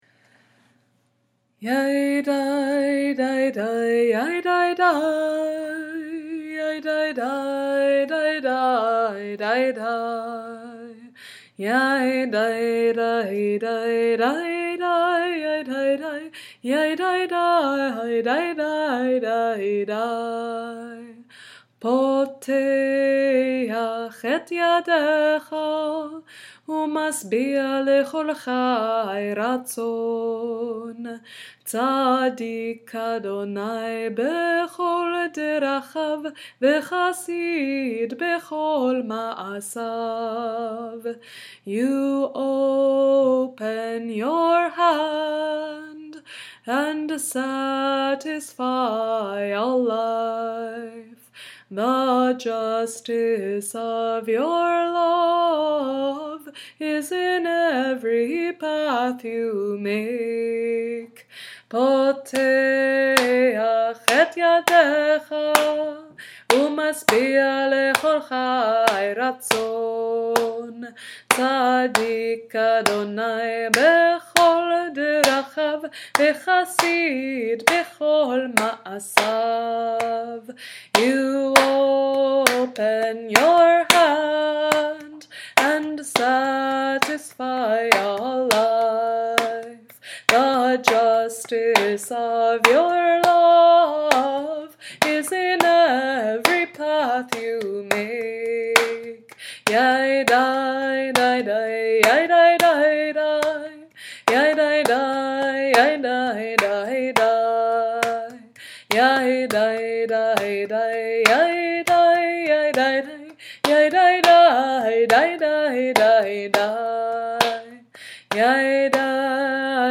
I enjoy using the traditional nusach, the melodies and modes of the Jewish year, to compose and arrange cantorial and congregational tunes and settings for Jewish liturgy.
Poteiakh et-yadekha, Hebrew from Psalm 145 (“Ashrei”), original English translation, created for a minchah (afternoon) service at the New Mexico/Mexico border wall near El Paso on a clergy witness trip to the border with T’ruah and HIAS.